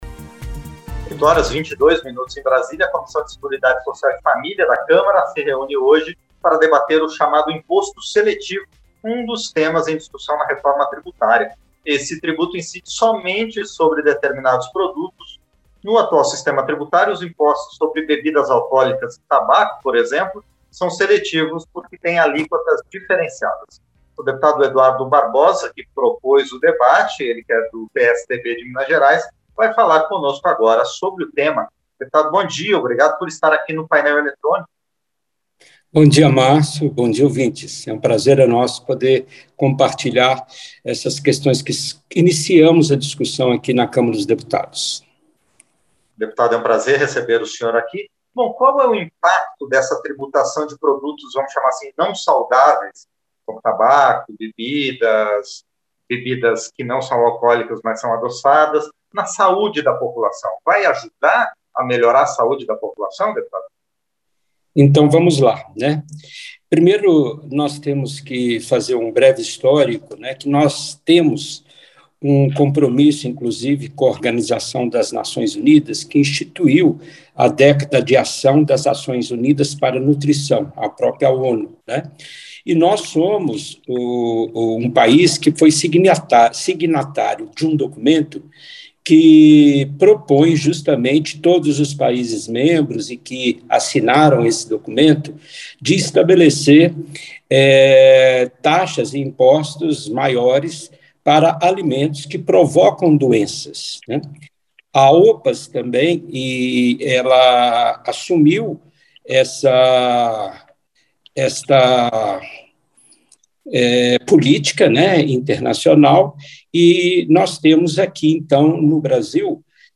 Entrevista - Dep. Eduardo Barbosa (PSDB-MG)